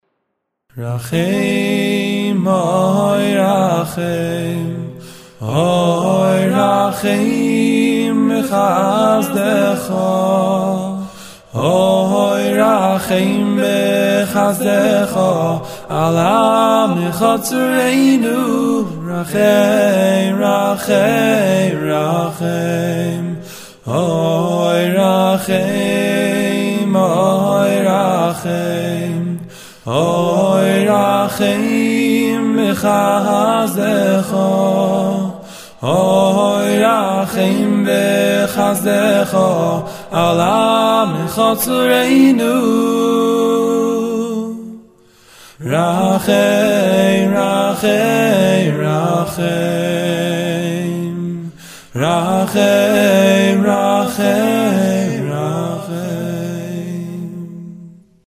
קטע נסיון וואקלי שעשיתי עם חבר כדי לבדוק את הציוד שלי
שימו לב לחמימות הנעימה של הקול, אני מאוד הייתי מרוצה מהתוצאה, ע"פ המבחן של הרמת ווליום גבוה והאוזן לא כואבת, זה פשוט נעים, ולא, אין לו קול מיוחד.
קול ראשון שני ובס/נמוך מה דעתכם???